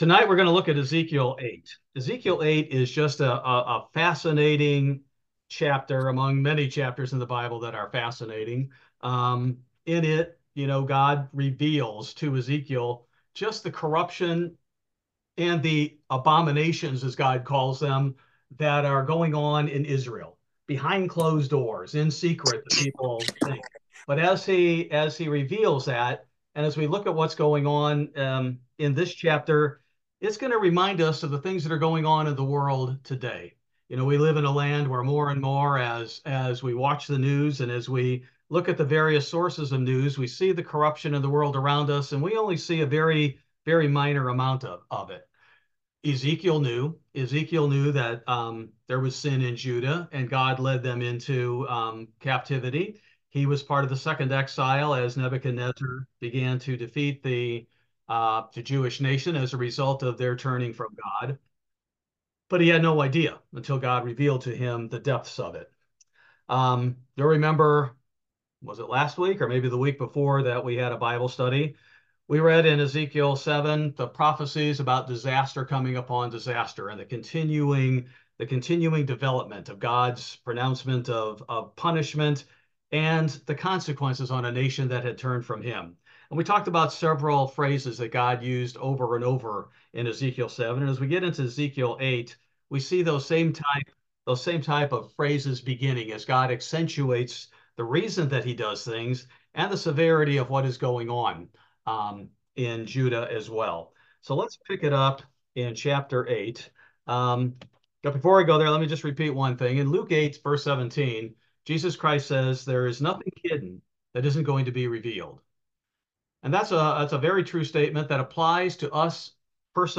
Bible Study: May 15, 2024